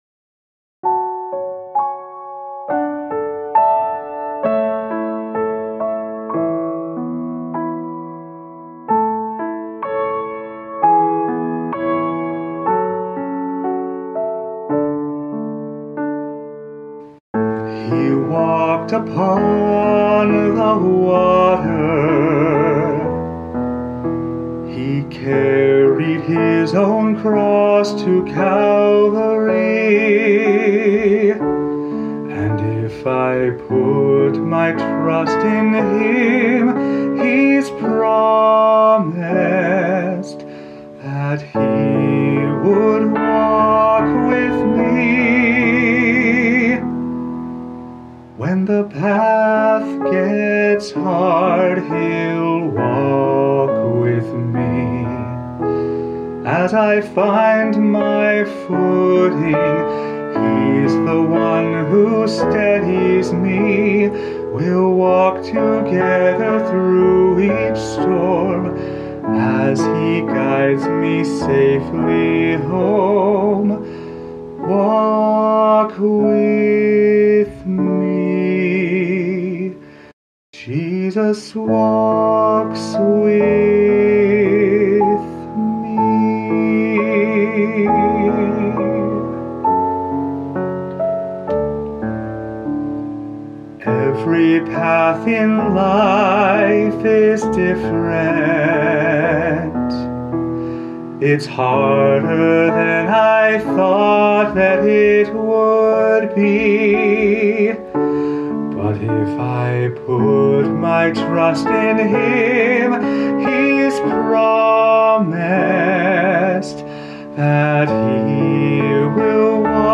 Voicing/Instrumentation: Vocal Solo